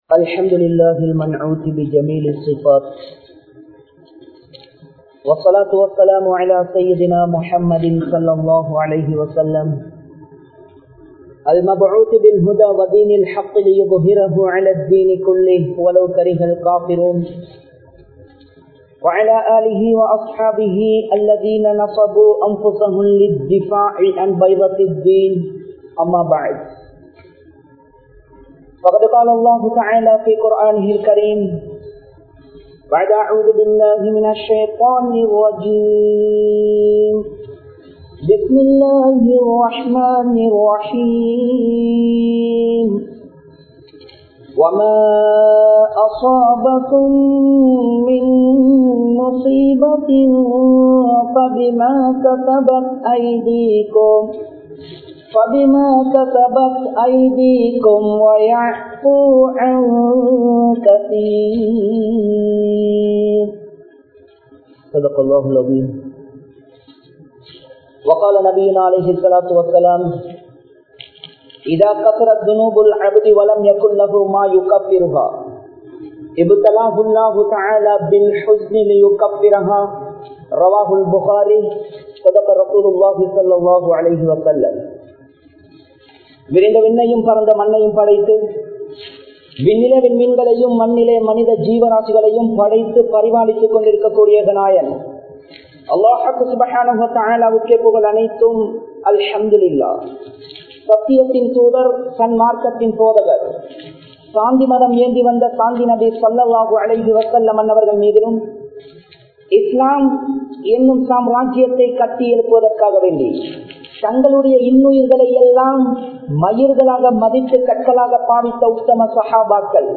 Soathanaihal Varak Kaaranam Enna? (சோதனைகள் வரக் காரணம் என்ன?) | Audio Bayans | All Ceylon Muslim Youth Community | Addalaichenai